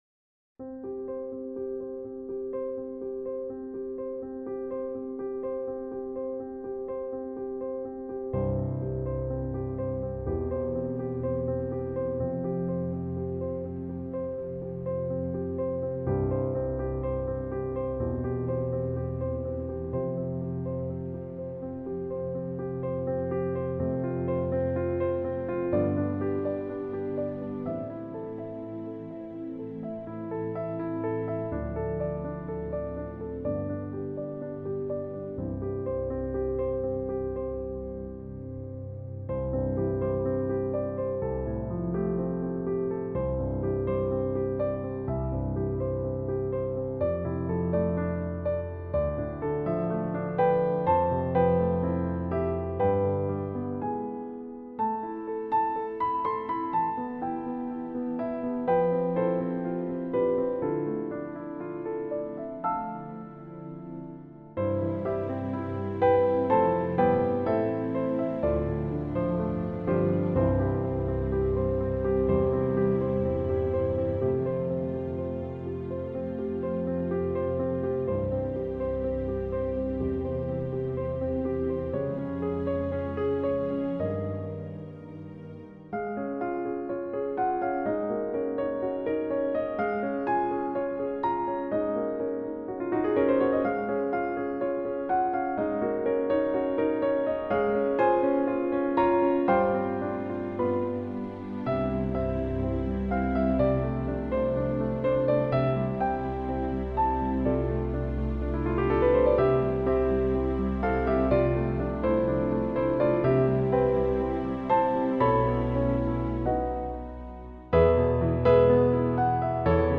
A service for 21st March 2021